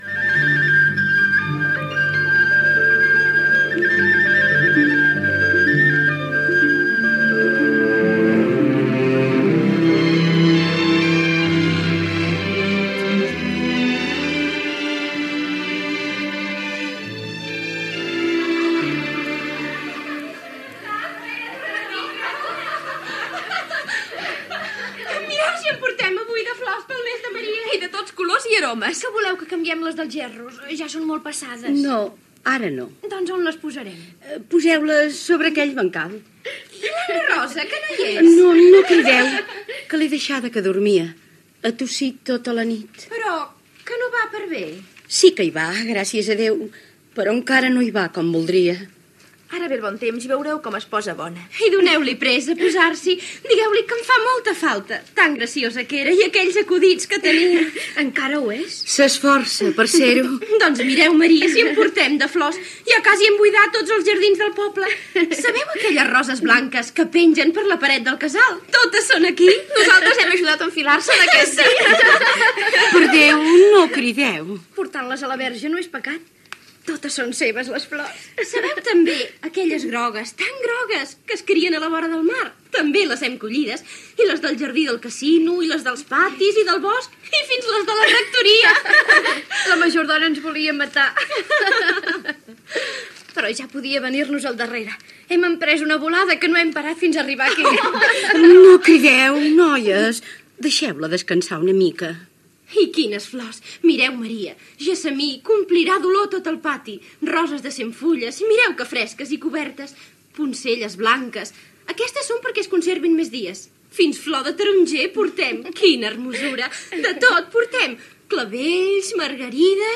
Adaptació radiofònica de l'obra "El pati blau" de Santiago Rusiñol. Primeres escenes de l'obra: les dones porten flors per preparar l'altar i la protagonista, una vídua, demana diners a un parent que ha fet fortuna a Amèrica i es vol quedar amb la casa
Ficció